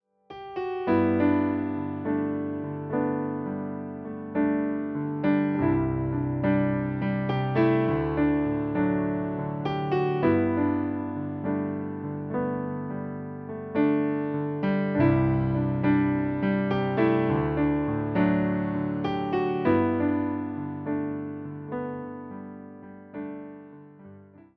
51 BPM
G – dur